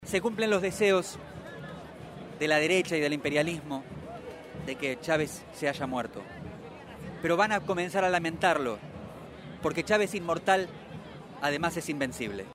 Radio Gráfica se hizo presente y entrevistó a militantes políticos, periodistas y referentes de la juventud para transmitir testimonio de la significancia del gran revolucionario de éste siglo.